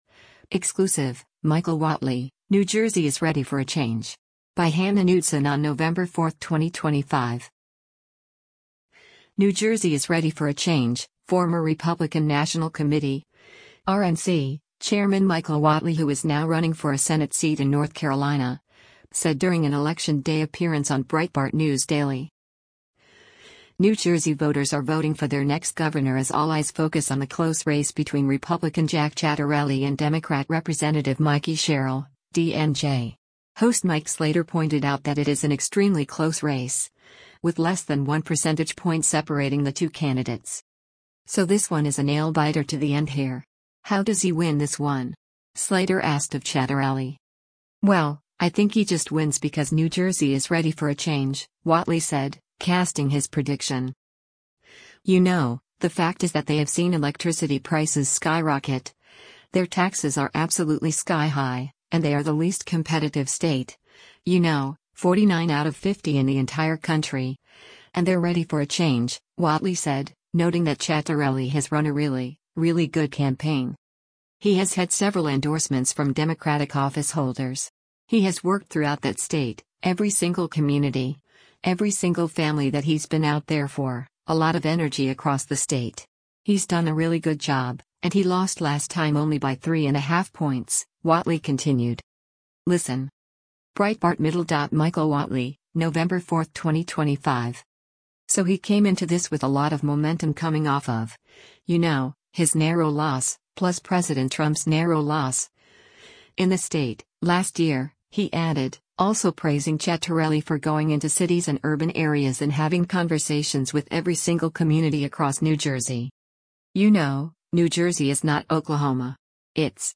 New Jersey is “ready for a change,” former Republican National Committee (RNC) Chairman Michael Whatley –who is now running for a Senate seat in North Carolina – said during an Election Day appearance on Breitbart News Daily.